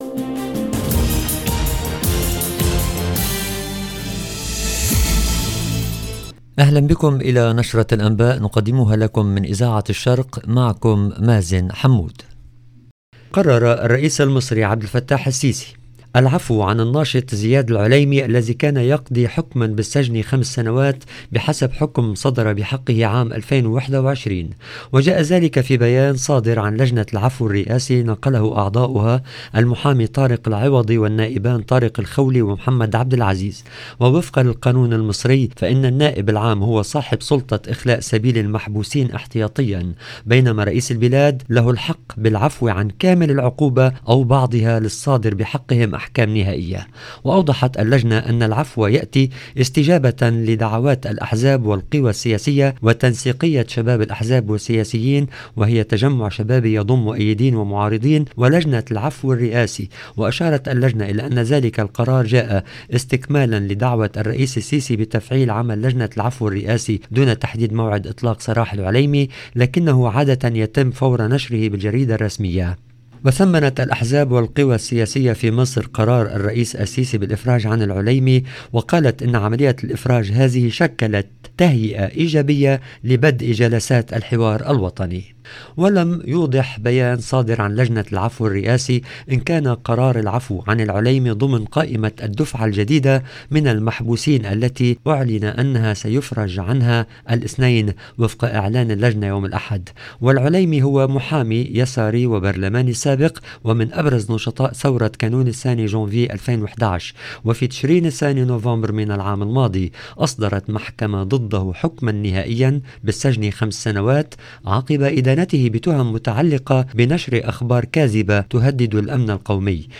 LE JOURNAL DU SOIR EN LANGUE ARABE DU 24/10/22